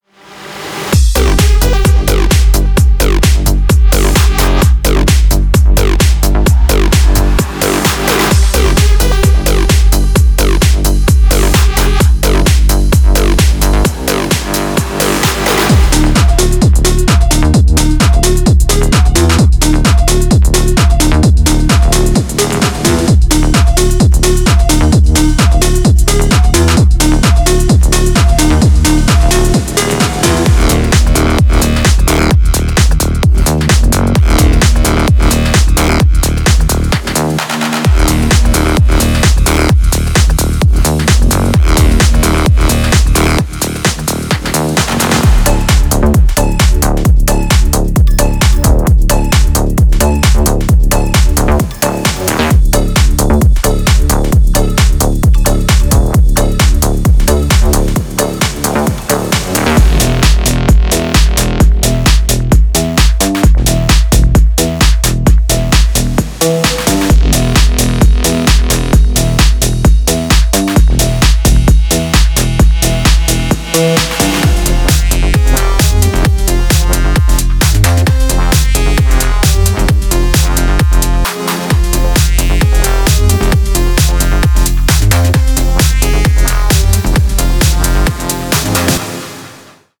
Type: Midi Samples
Electro House House Tech House